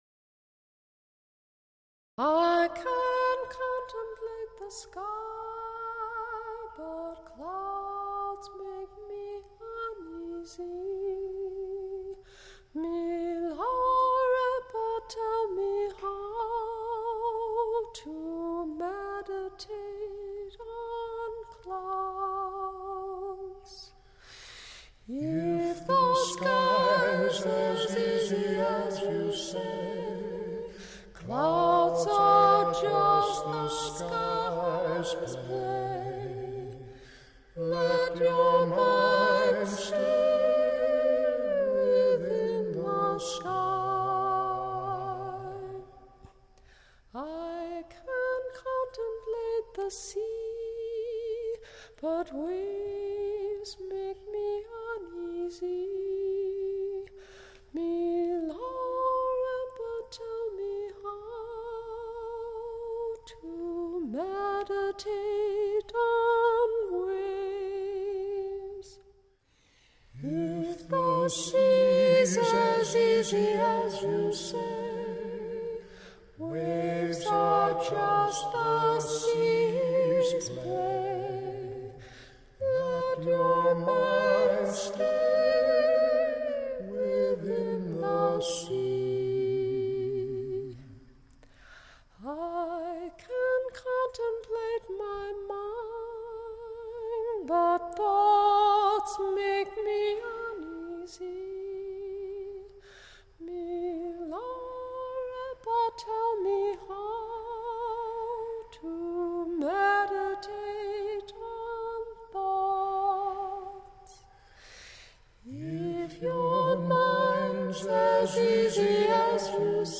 Song of Paldarbom - duet (Download 2.20 MB)
Dharma song based on the poetry of Milarepa (1052-1135)
Paldarbom_duet.mp3